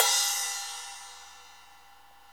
CYM X12 SP0Z.wav